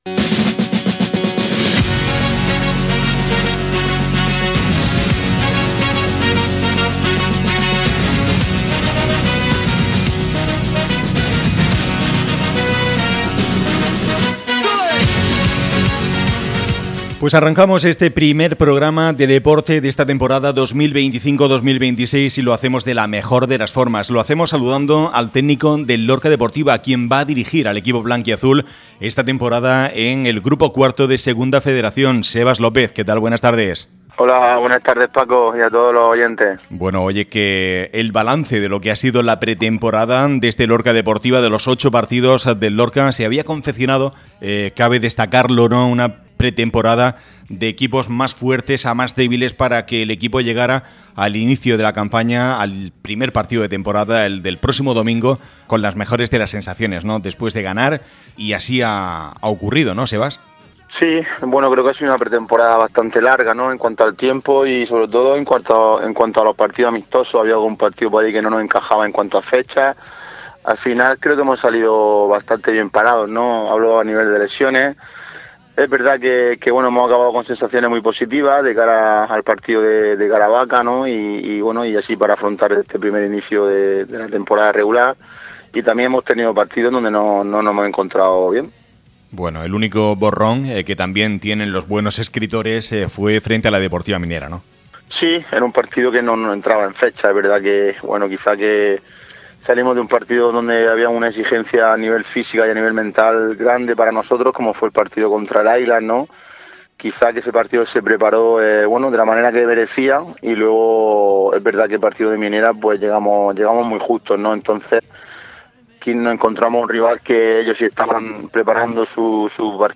ÁREA LORCA RADIO. Deportes.